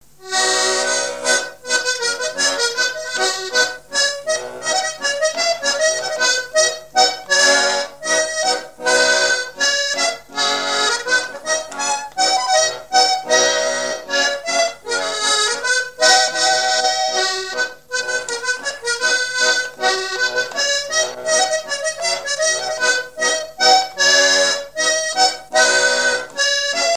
Chants brefs - A danser
danse : scottich sept pas
Pièce musicale inédite